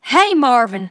synthetic-wakewords
ovos-tts-plugin-deepponies_Applejack_en.wav